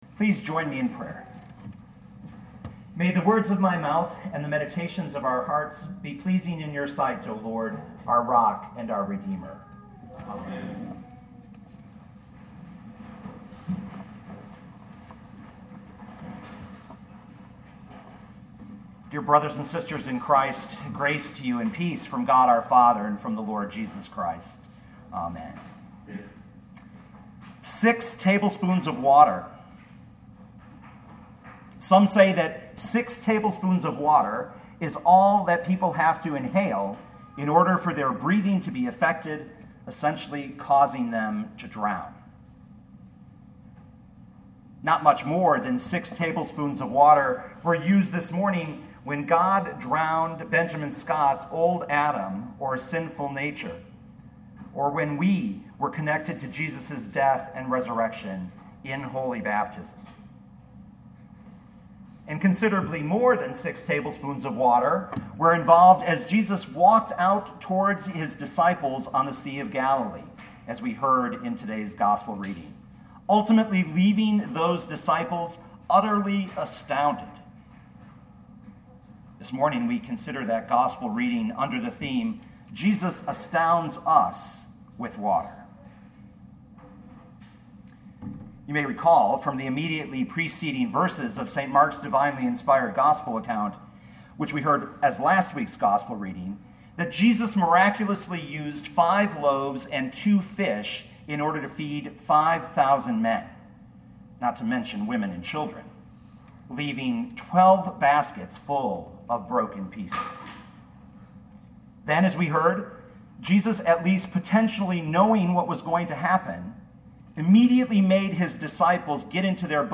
2018 Mark 6:45-56 Listen to the sermon with the player below, or, download the audio.